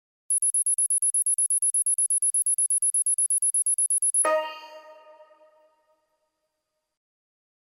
Звуки загрузки файла